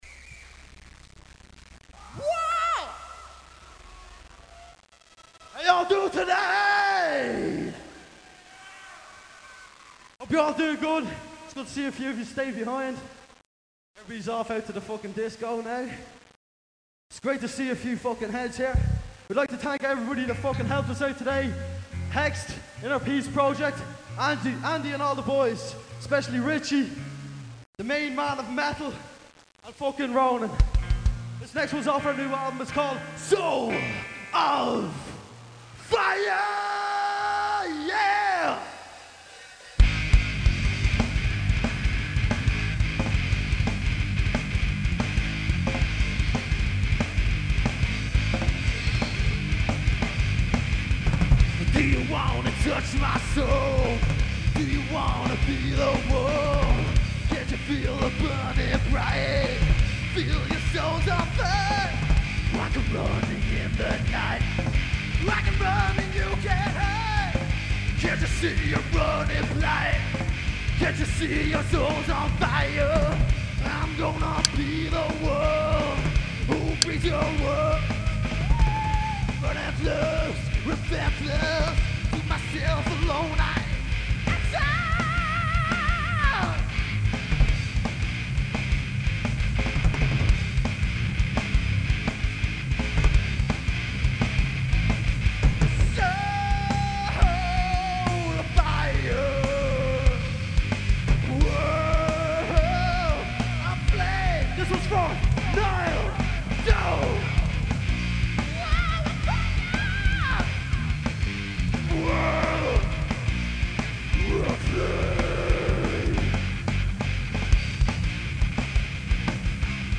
Recorded live in the Rosetta, Belfast.